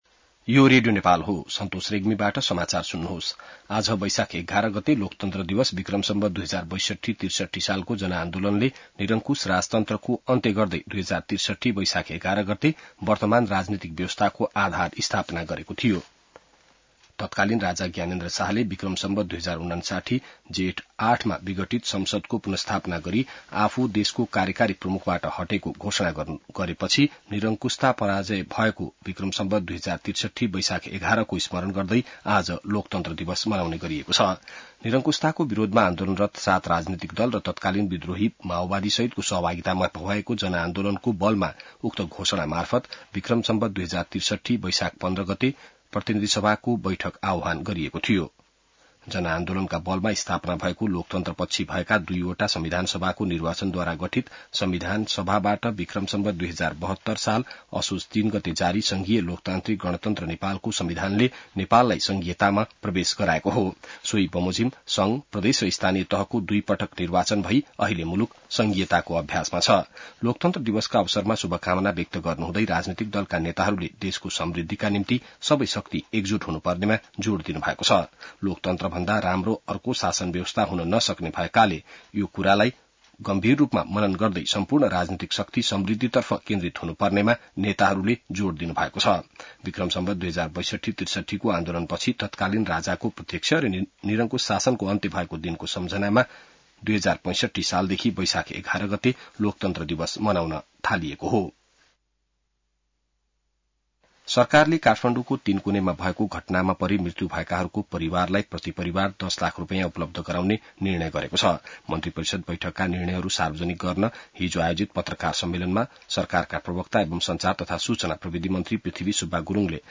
बिहान ६ बजेको नेपाली समाचार : ११ वैशाख , २०८२